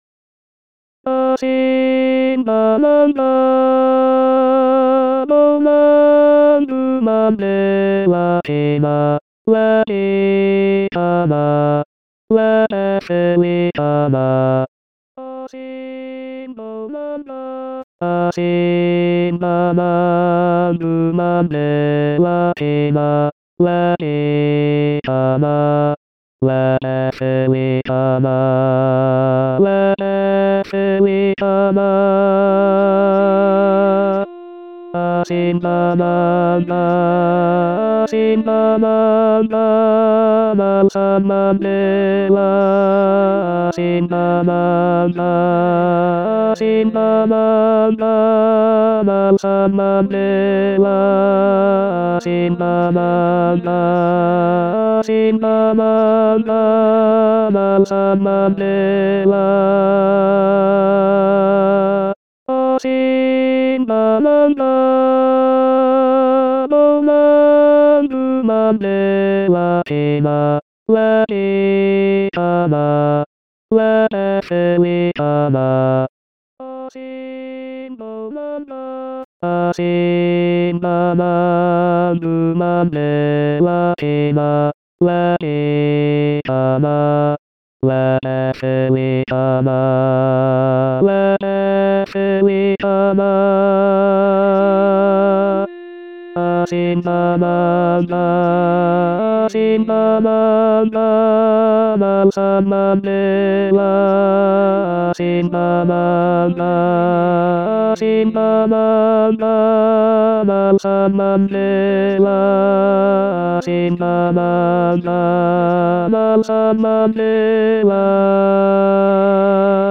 Asimbonanga tEnors 1.mp3